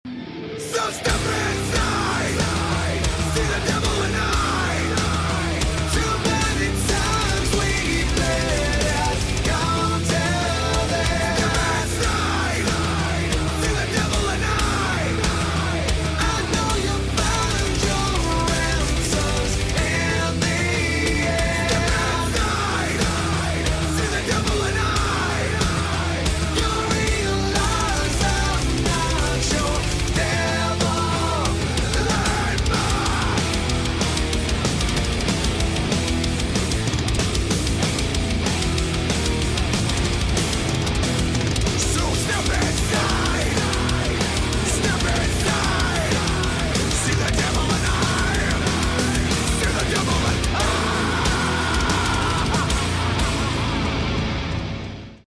a haunting anthem
Dark, menacing, and unrelenting